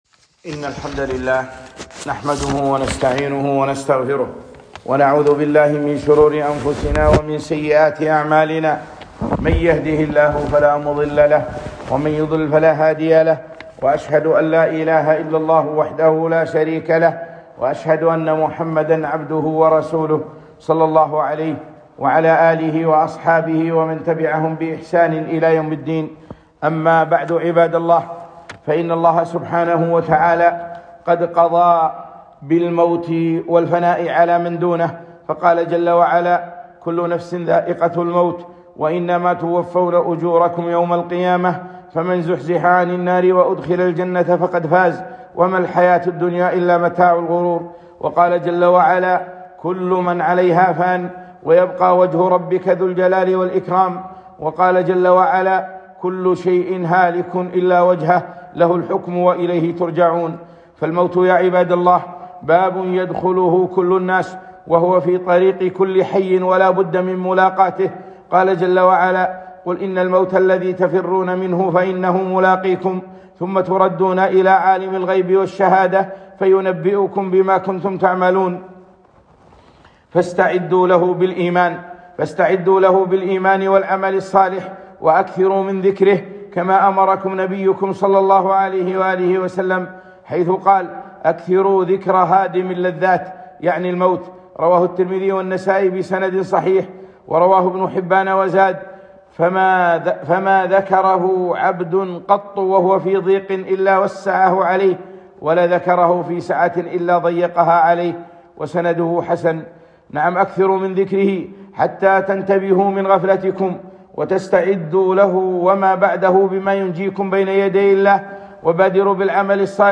خطبة - الـمـوت 7-7-1442